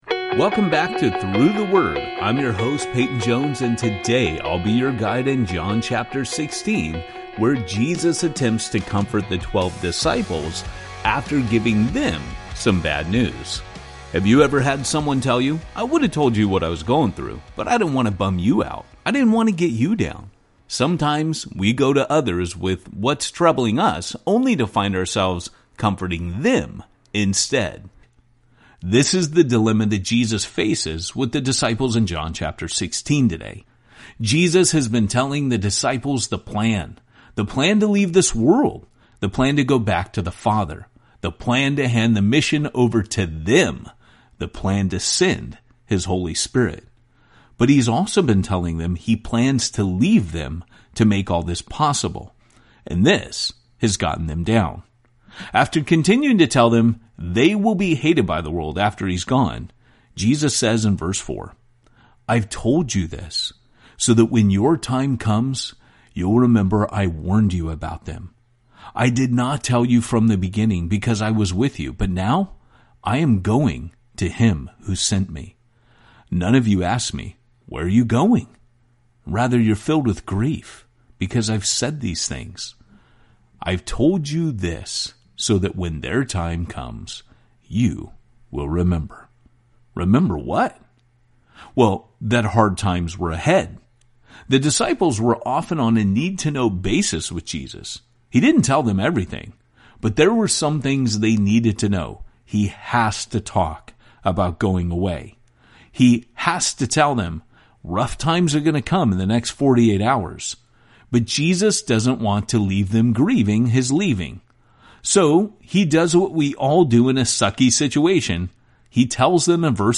The story comes alive each day as Through the Word’s ten-minute audio guides walk you through each chapter with clear explanation and engaging storytelling.